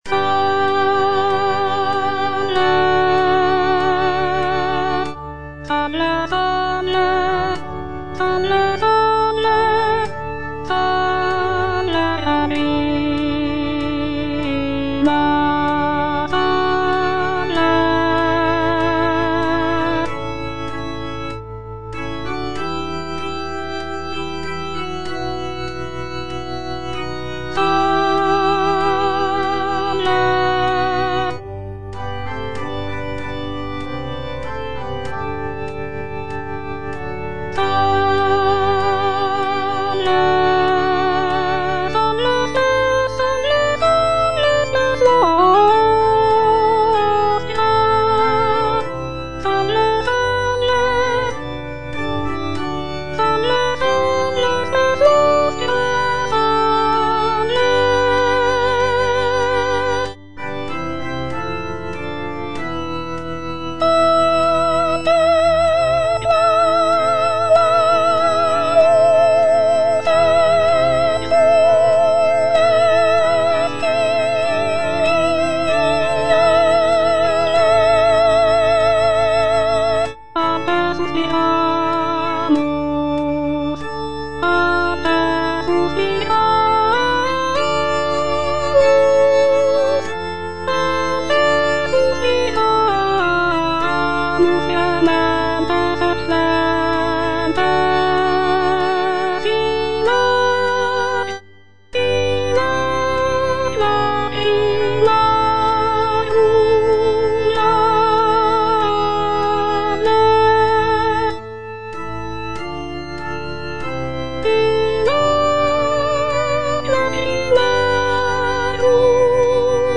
G.F. SANCES - SALVE, REGINA Soprano (Voice with metronome) Ads stop: auto-stop
"Salve, Regina" by Giovanni Felice Sances is a sacred vocal work written in the 17th century.
The piece is written for soprano soloist and continuo accompaniment, and features a lyrical and expressive melody that showcases the singer's vocal abilities.